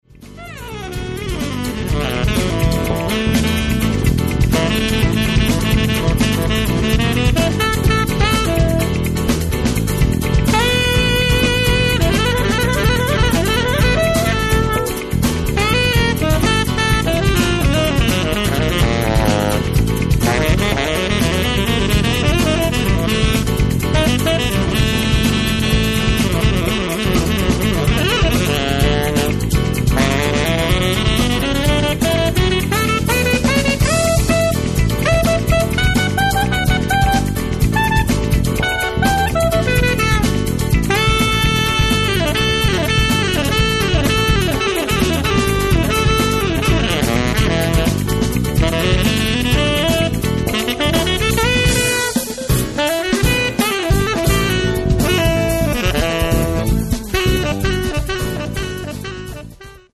tromba
batteria
sax